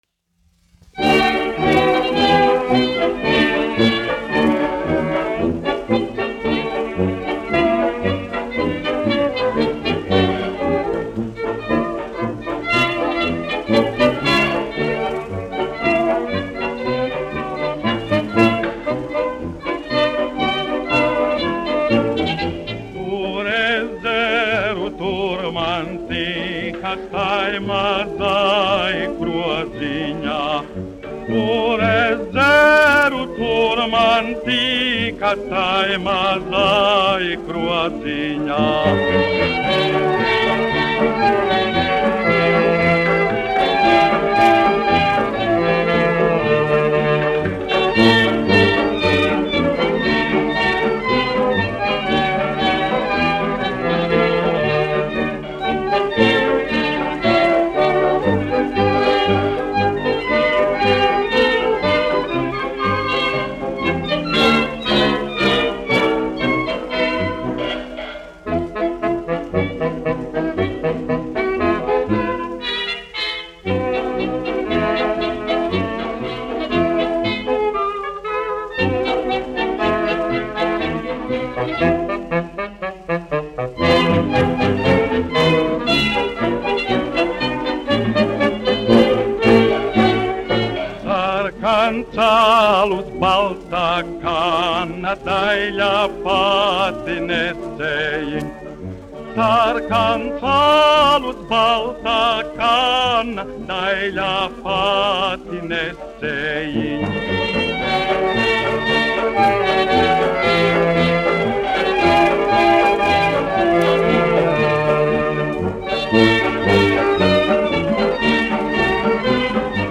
Arnolds Auziņš, aranžētājs
1 skpl. : analogs, 78 apgr/min, mono ; 25 cm
Fokstroti
Latviešu tautasdziesmas
Skaņuplate
Latvijas vēsturiskie šellaka skaņuplašu ieraksti (Kolekcija)